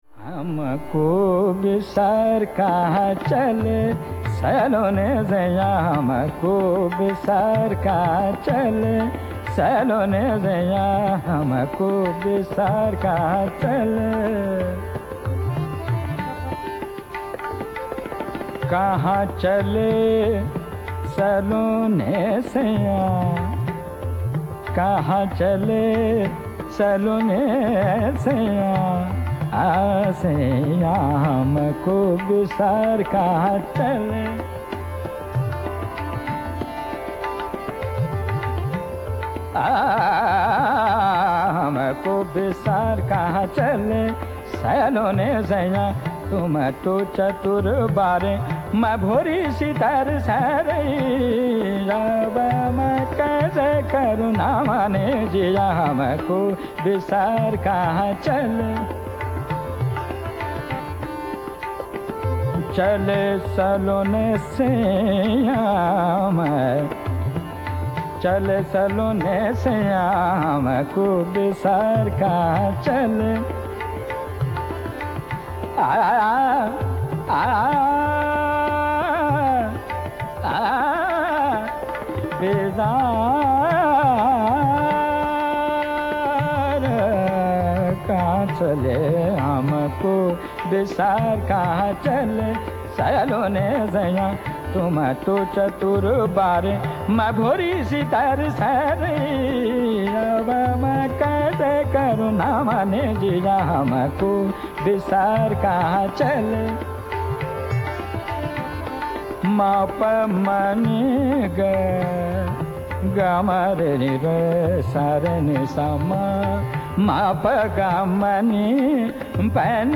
the plot centres around a peculiar prayoga containing shuddha dhaivat.  A charming composition this: hamko bisara kahan chali.